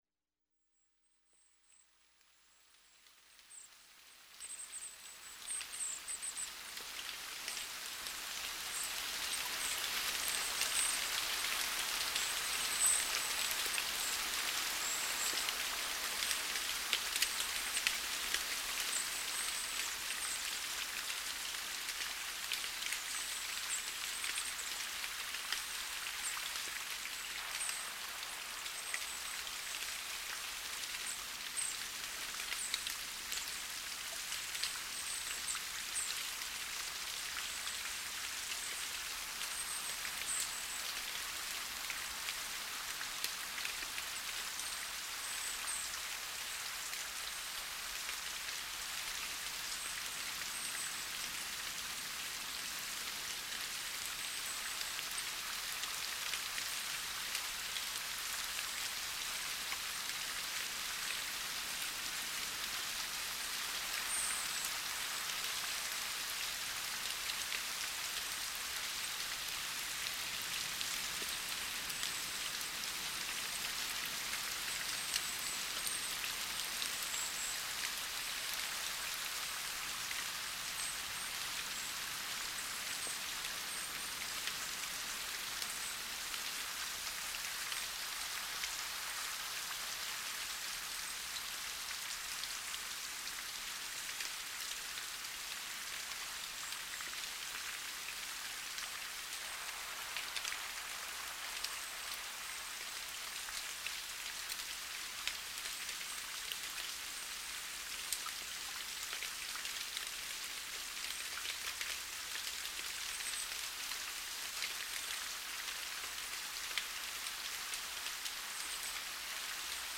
sounds_of_nature_-_gentle_rain_in_the_forest.mp3